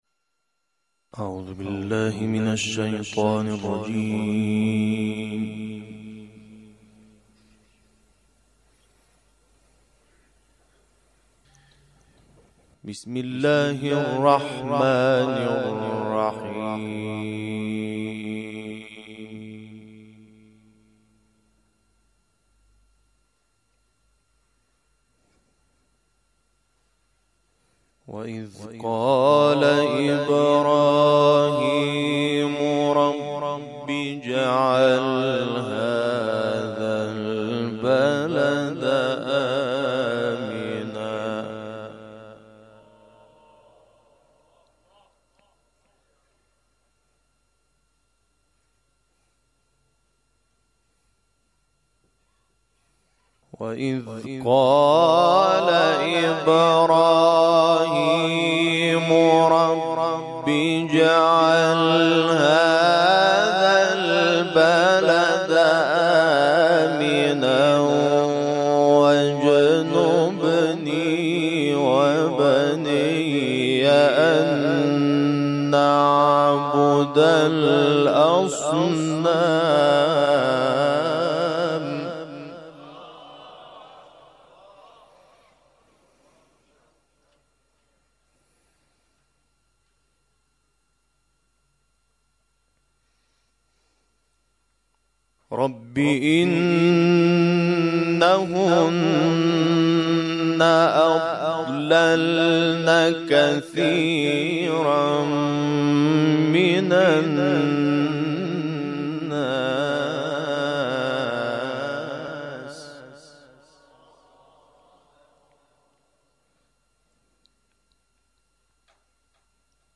تلاوت آیات ۴۱-۳۵ سوره ابراهیم و سُوَر حمد و کوثر
قاری جوان مصری از آیات ۴۱ -۳۵ سوره ابراهیم و سوره‌های کوثر و حمد ارائه می‌شود.